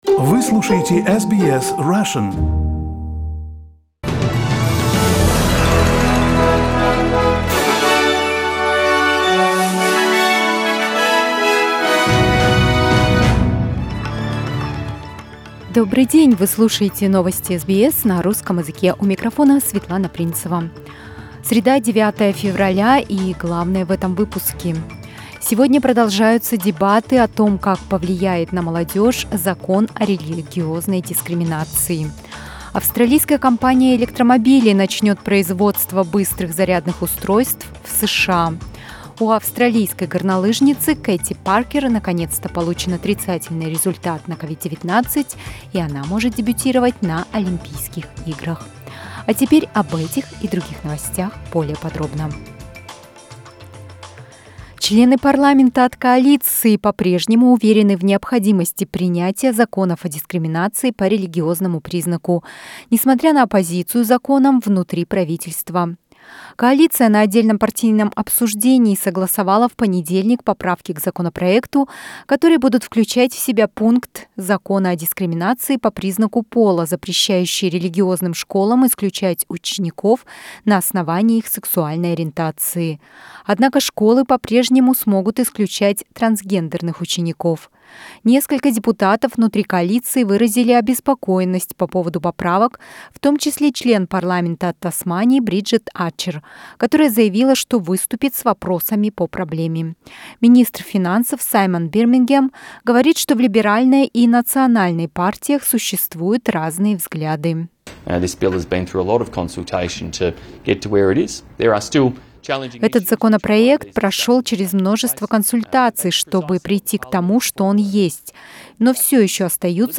Listen to the top news headlines from Australia and the world on SBS Russian.